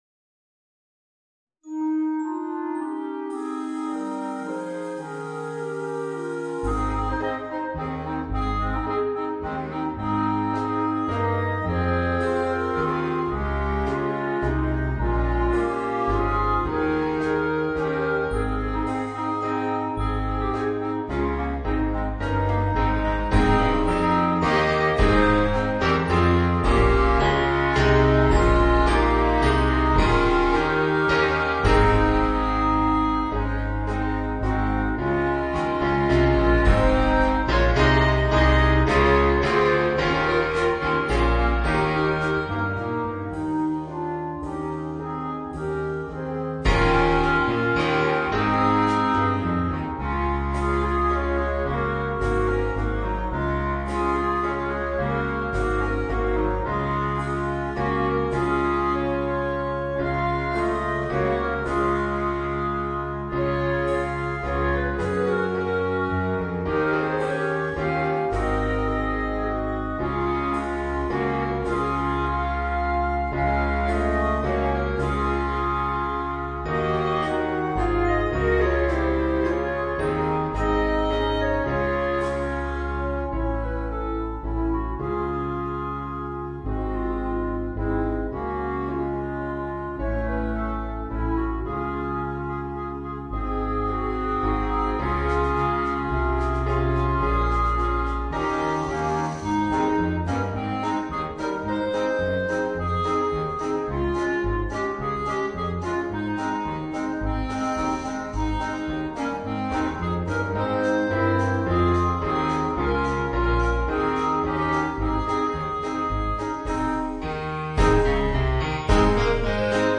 Voicing: Woodwind Quartet and Rhythm Section